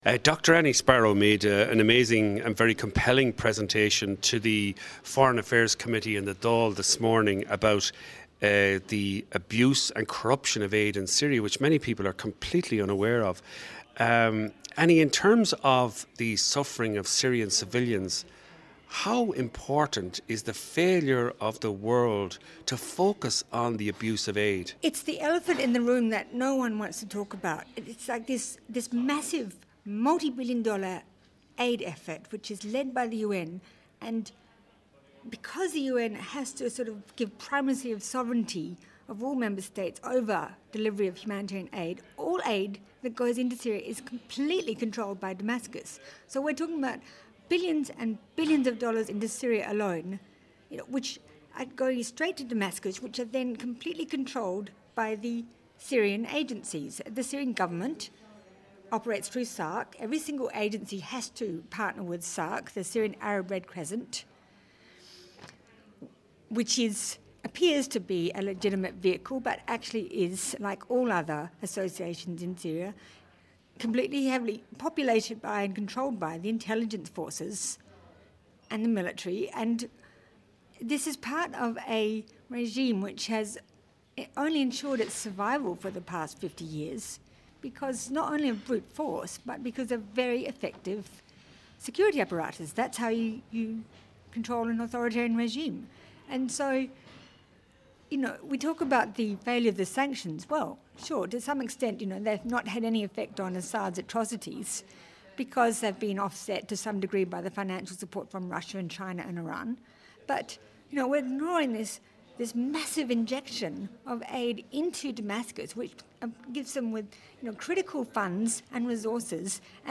How Syria's Assad regime steals billions in UN humanitarian aid - Interview